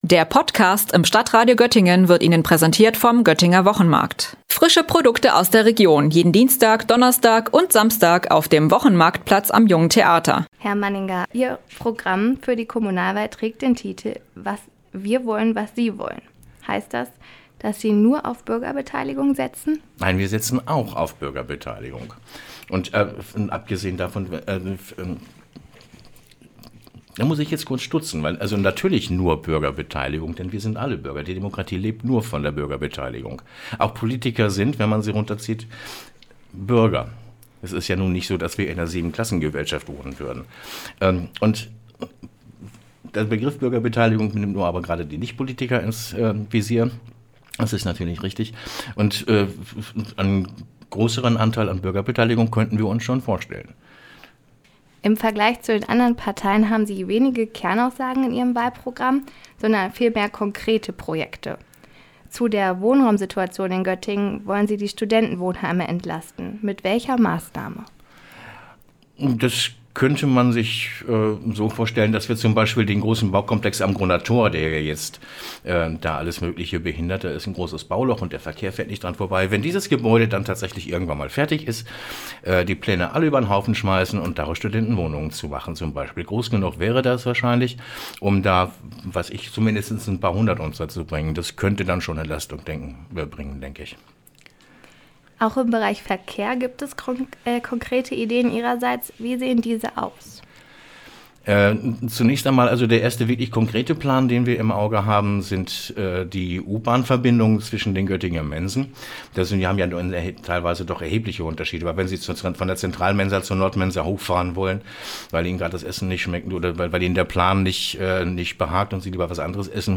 Am Sonntag wird in Göttingen die neue Besetzung des Stadtrates gewählt. In unserer Interview-Reihe blicken wir jeden Tag mit einem Kandidaten auf das Wahlprogramm seiner Partei.